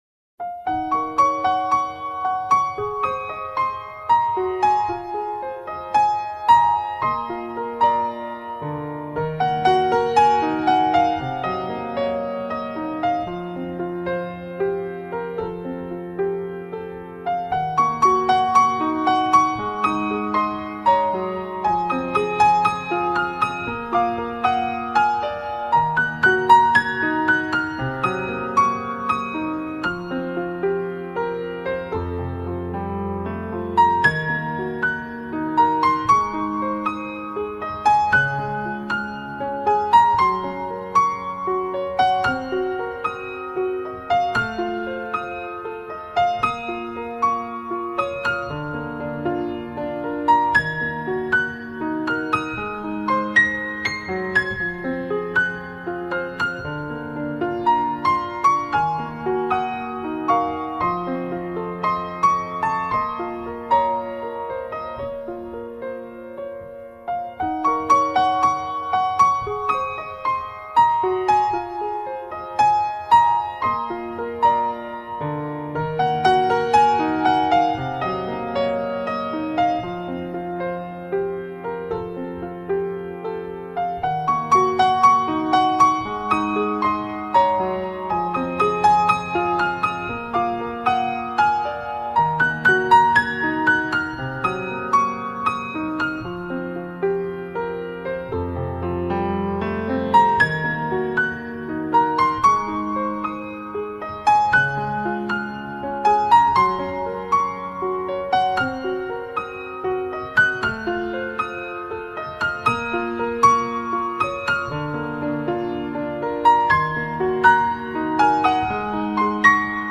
背景音乐为抒情钢琴曲爱情背景配乐
该BGM音质清晰、流畅，源文件无声音水印干扰